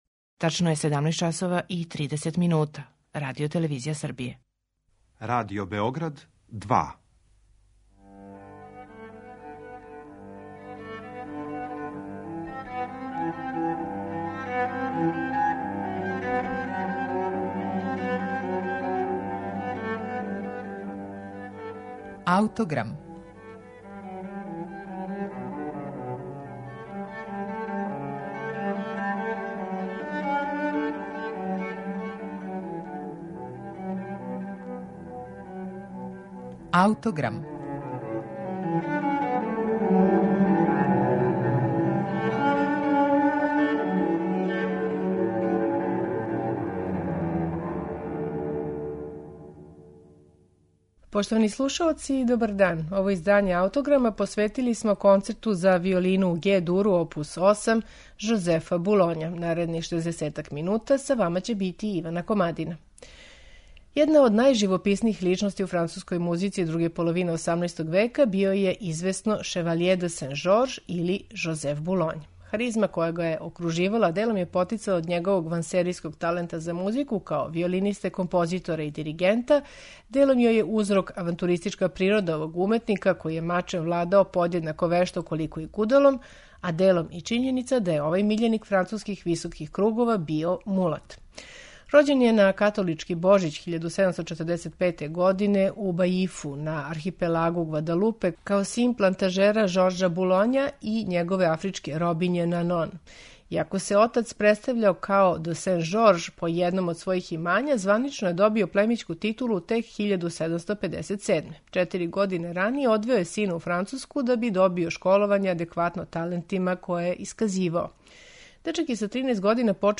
Данашњу емисију посветили смо Концерту за виолину у Гe-дуру, опус 8, Жозефа Булоња
Чућемо га у интерпретацији коју су остварили Жан-Жак Канторов и Камерни оркестар Бернарда Томаса.